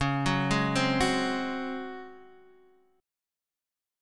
DbM7sus4 chord